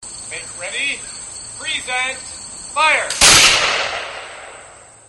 Spin Kick
Spin Kick sound effect for fight or game sound effects.